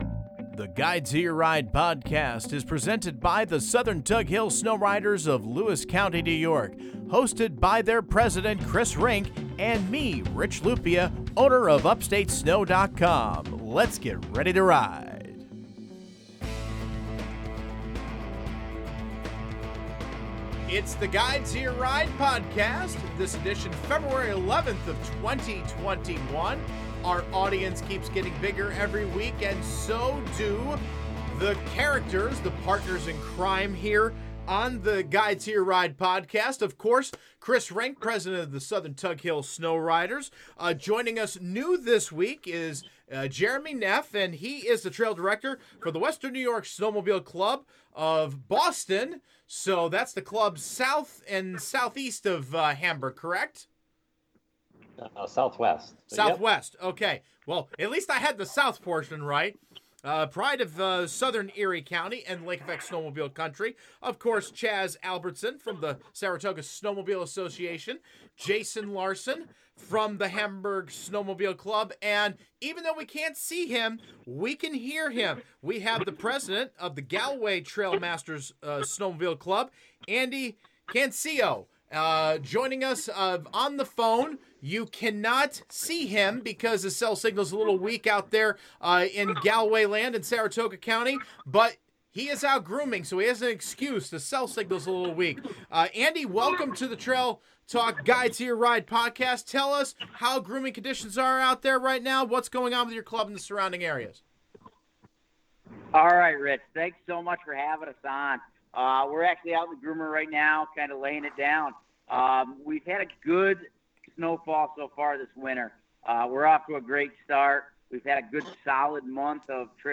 This is the audio only version.